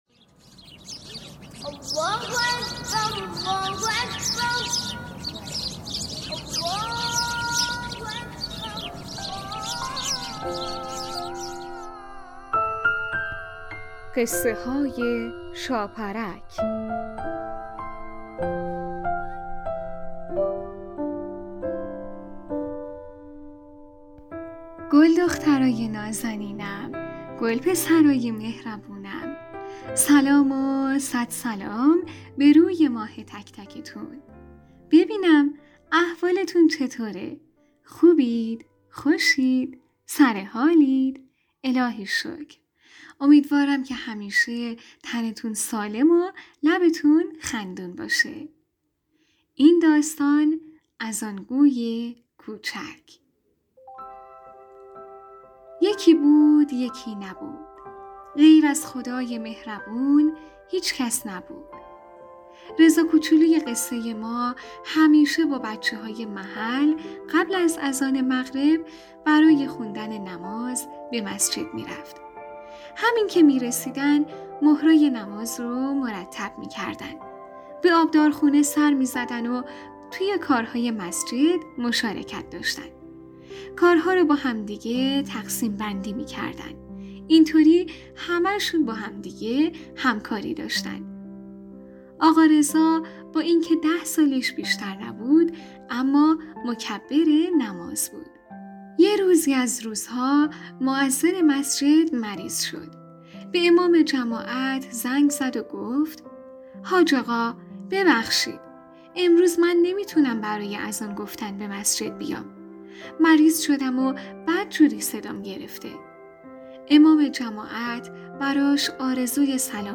قسمت صد و بیست و هفتم برنامه رادیویی قصه های شاپرک با نام اذان‌گوی کوچک یک داستان کودکانه مذهبی با موضوعیت نماز